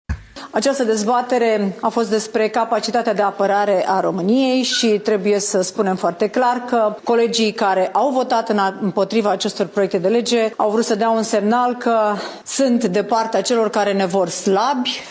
Senatoarea PNL Nicoleta Pauliuc, preşedinta Comisiei pentru apărare, a subliniat că şi-ar dori ca aceste proiecte de lege să fie susţinute de toate partidele parlamentare, pentru că – spune senatoarea – e vorba despre securitatea românilor.